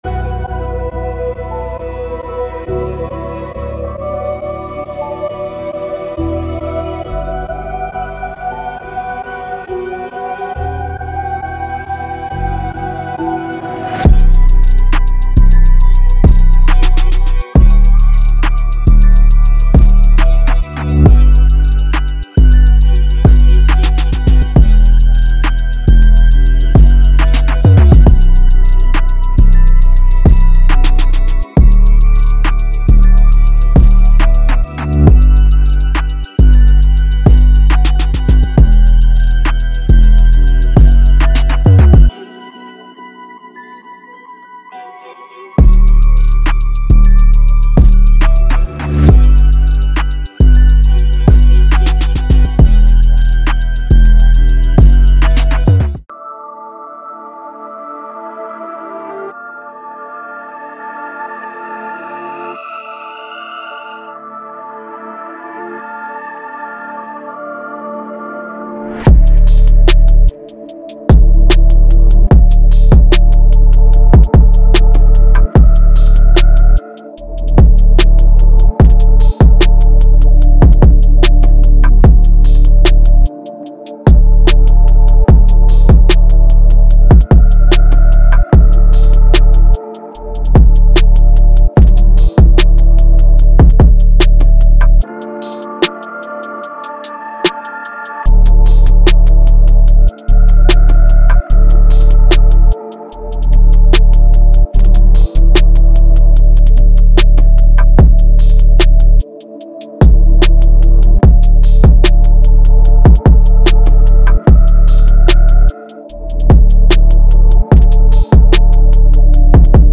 Trap construction kit
However, the guitars are played live, so they have no MIDI.
Demo